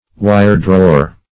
Wire-drawer \Wire"-draw`er\, n. One who draws metal into wire.